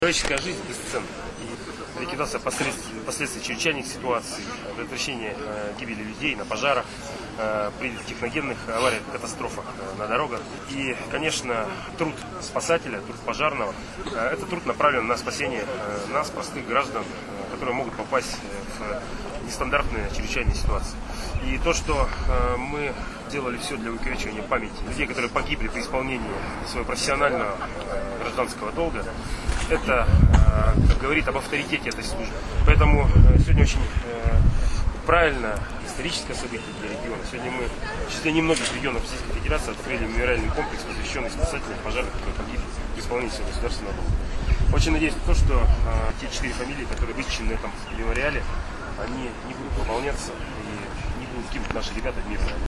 Памятник погибшим при исполнении долга сотрудникам МЧС открыли в Вологодской области. Торжественное открытие мемориала в Кириловском районе состоялось во вторник, 30 апреля.
Олег Кувшинников рассказывает об открытии памятника погибшим сотрудник